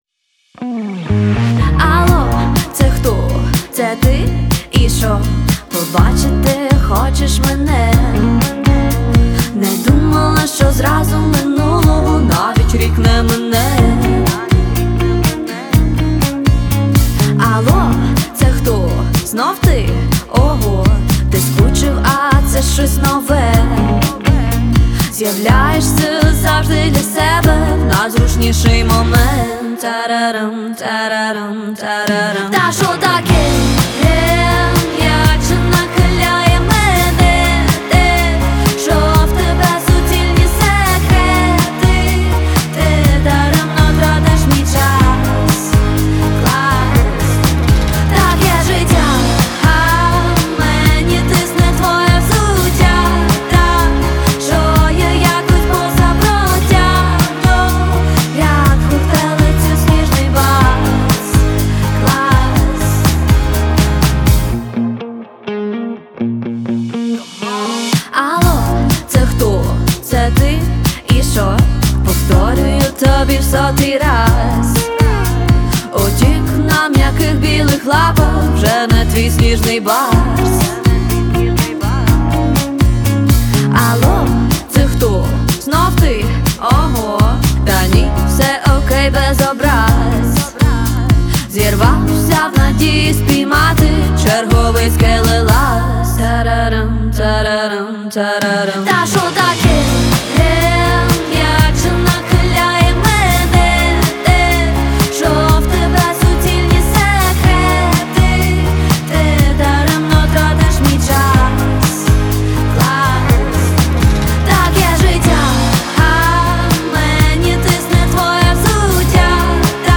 новий танцювальний поп-хіт від української співачки